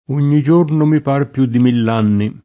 mille [m&lle] num.